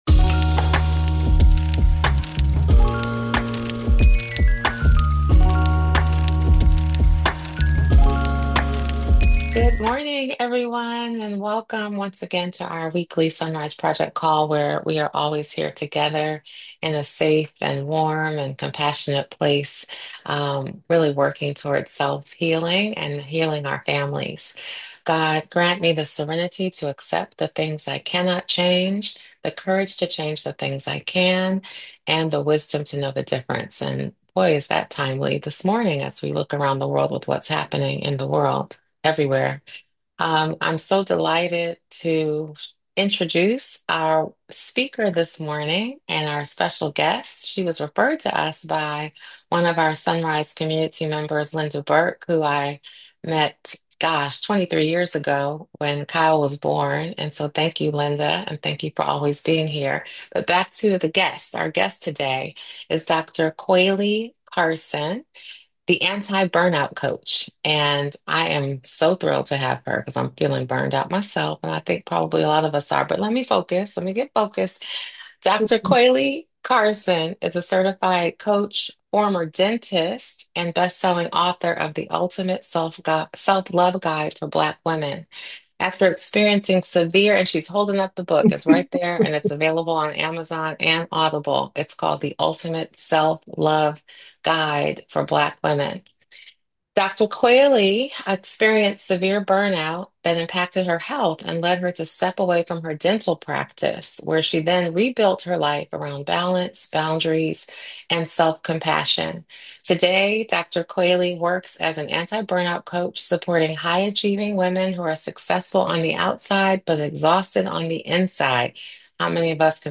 A timely conversation for anyone feeling stretched thin and running on empty.